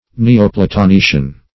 Neoplatonician \Ne`o*pla`to*ni"cian\, n.
neoplatonician.mp3